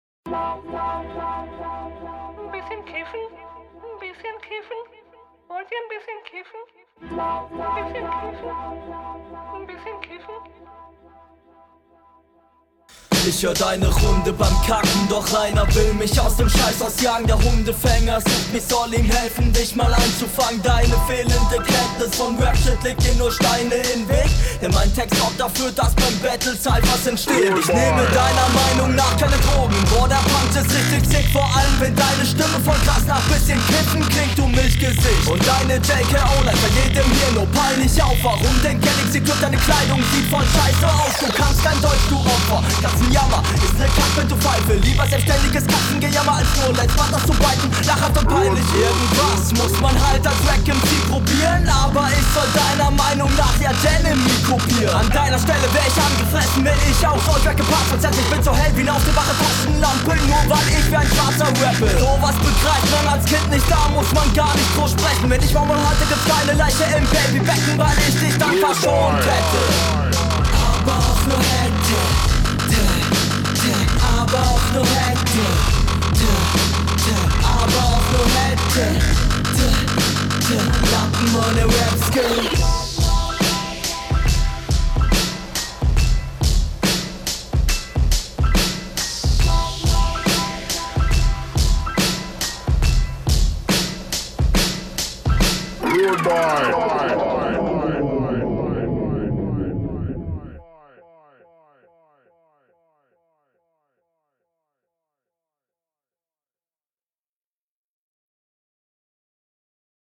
Ja kommst deutlich geiler und smoother auf dem Beat.